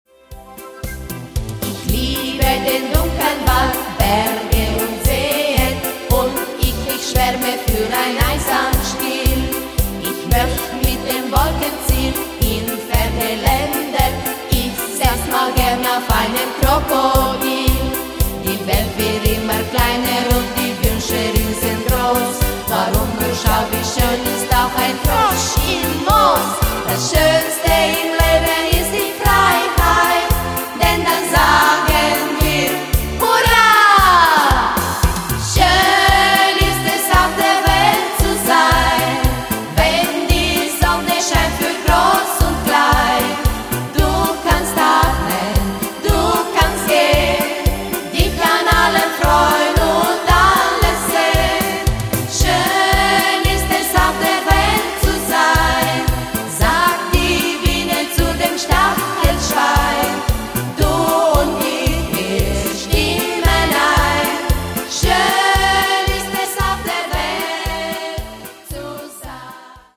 Vocalgruppen mit Begleitung der Melodies-Band.
Der Sound klingt so natürlich absolut Toll und motiviert.
Wir singen Schlager und Pop "wie die Grossen".
In unserem kleinen Studio produzieren wir eine eigene CD.
Kinder Pop-Chor.